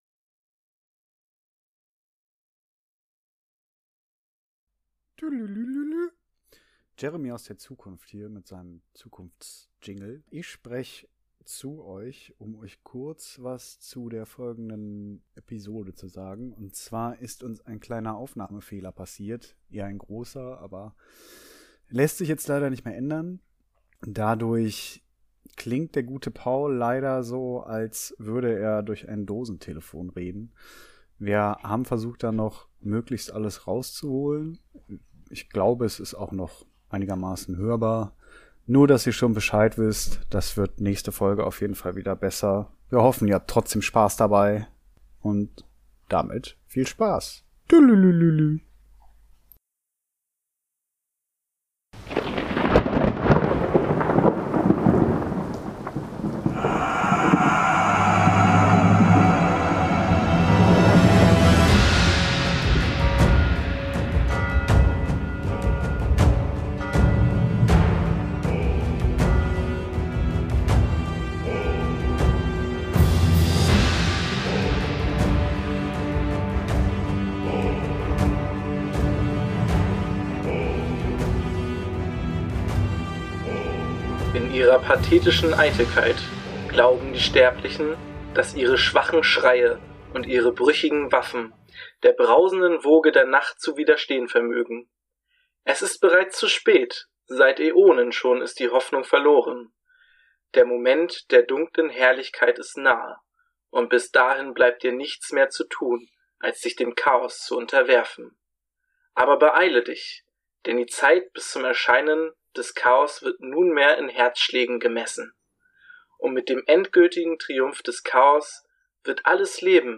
leider etwas blechernd